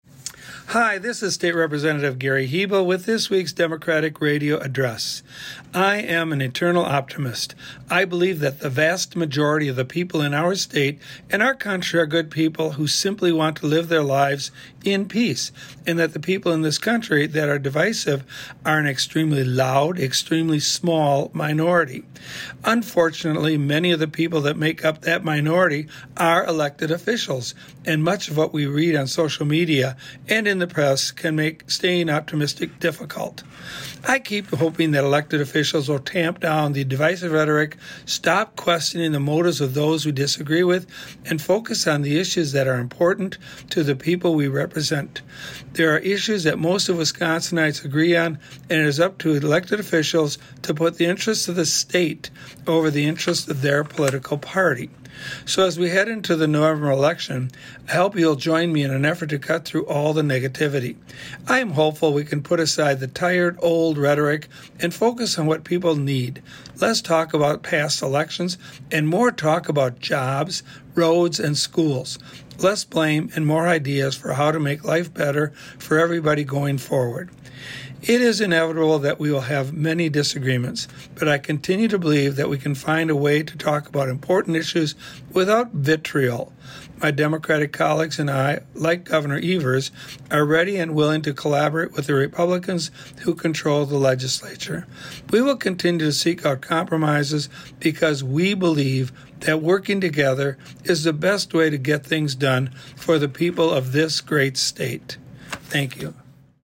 Weekly Dem radio address: Rep. Hebl says he wants divisive people to change course and focus on making life better - WisPolitics